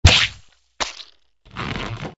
AA_drop_sandbag.ogg